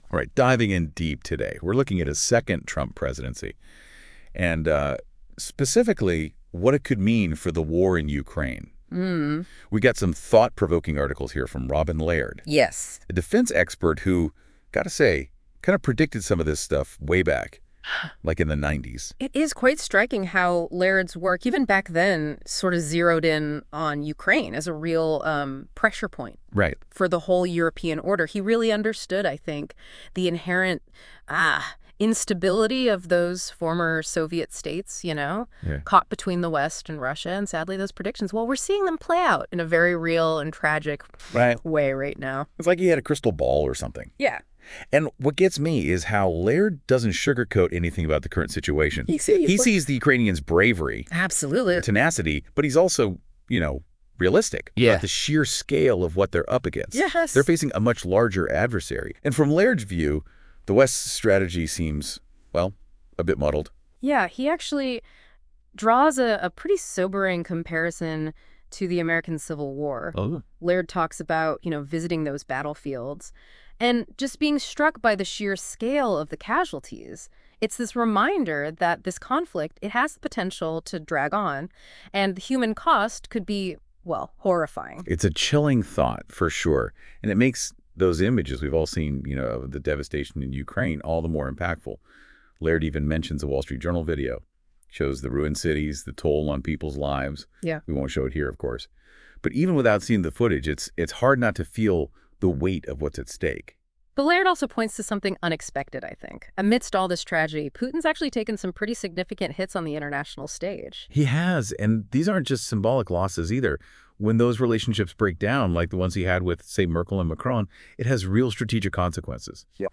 This podcast discusses these articles and is a Deep Dive podcast made via Notebook LM.